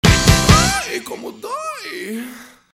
Final da música